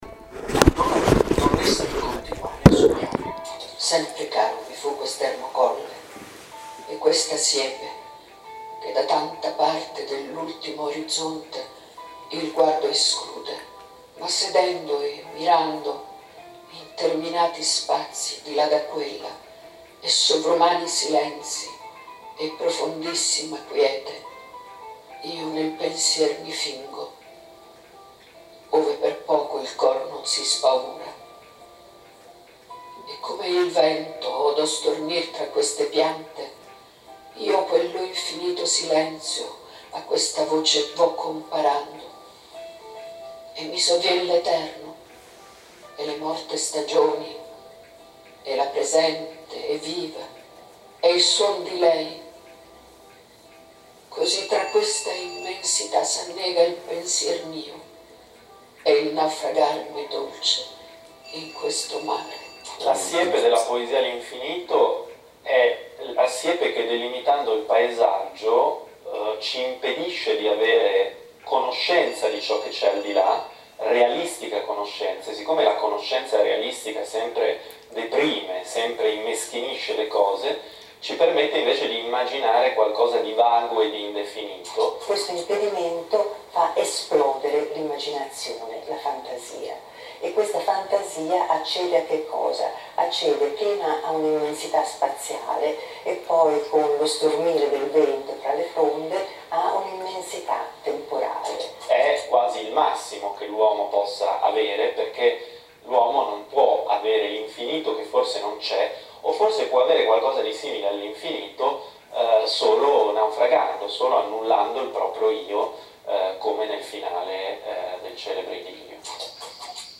La location scelta è il Parco dei mostri di Bomarzo, quasi la messa in scena di pietra del mondo dell’immaginazione in cui il giovane Leopardi cercava rifugio dalla realtà.